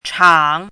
chinese-voice - 汉字语音库
chang3.mp3